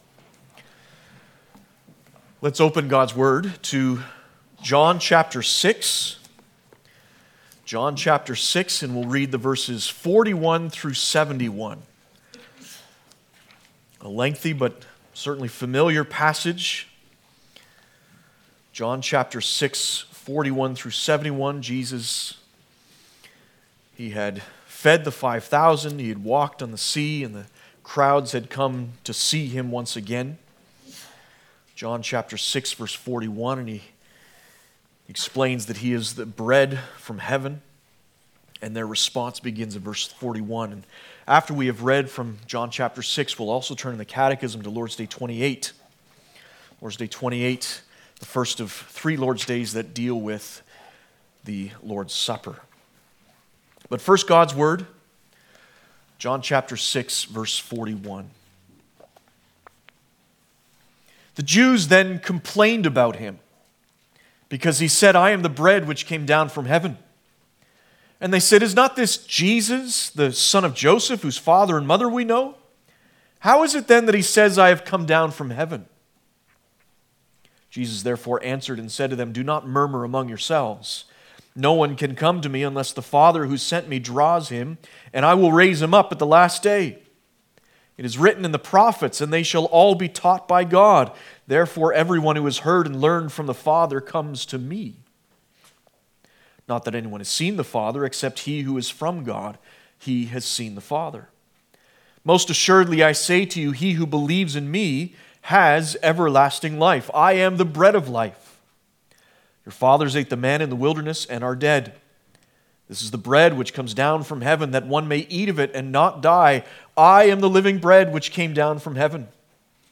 Passage: John 6:41-71 Service Type: Sunday Afternoon